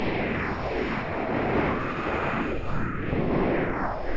demonwind01.ogg